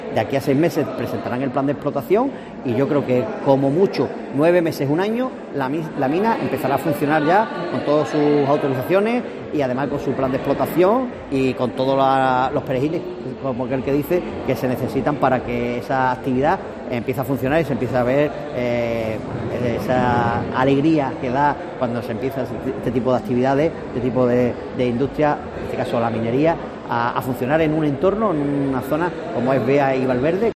Pepe Correa, delegado de la Junta en Huelva